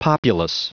Prononciation du mot populous en anglais (fichier audio)
Prononciation du mot : populous